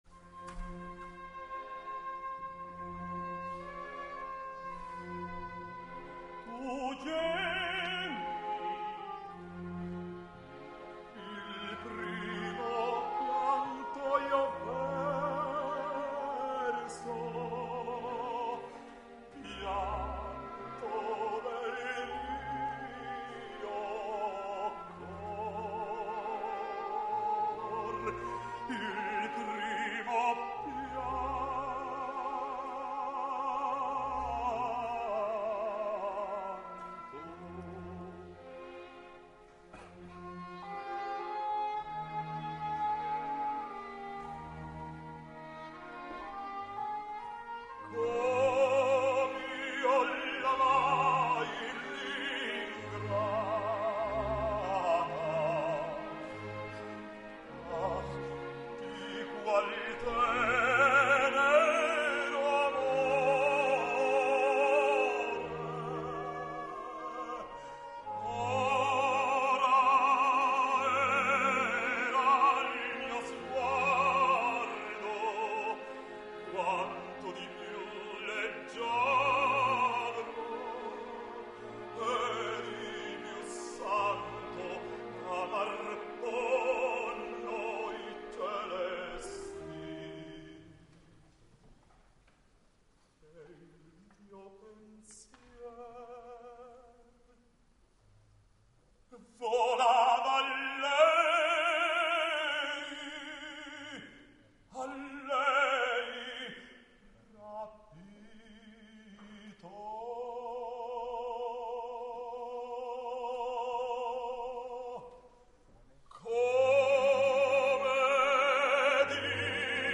Orosmane [Bass]